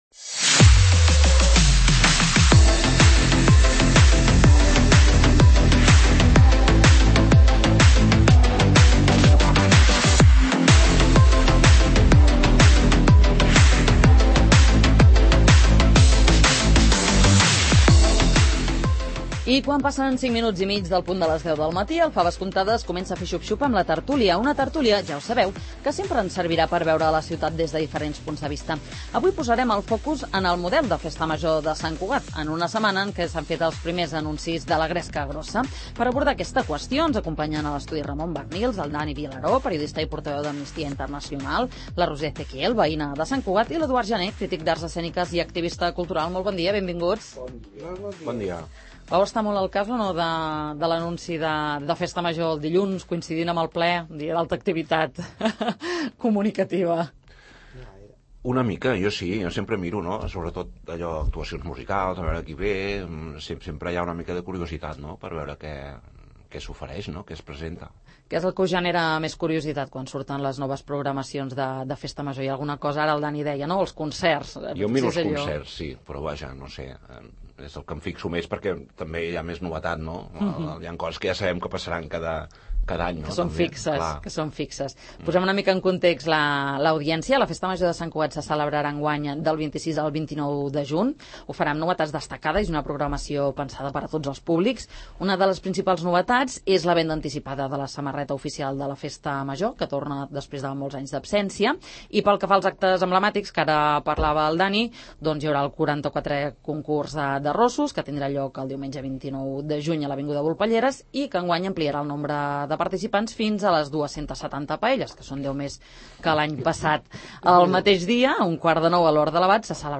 El model de Festa Major de Sant Cugat �s un �xit? En parlem a la tert�lia del 'Faves comptades'